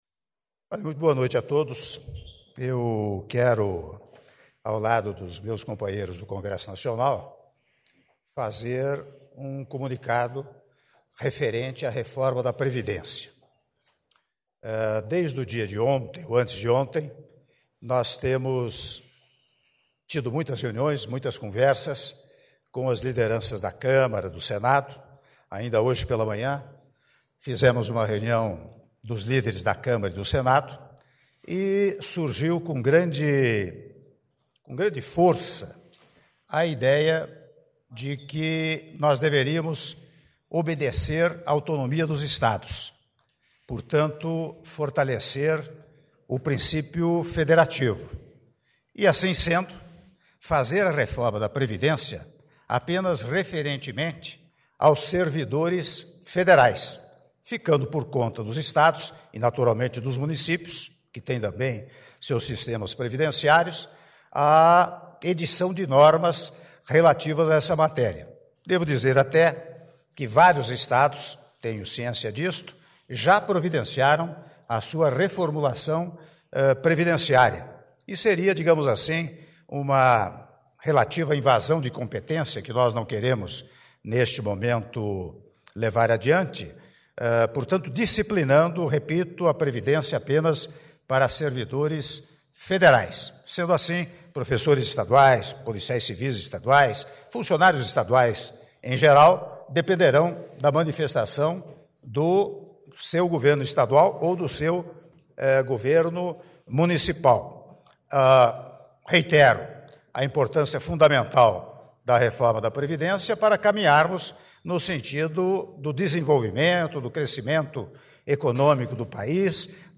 Áudio da declaração à imprensa do Presidente da República, Michel Temer - Brasília/DF (03min44s)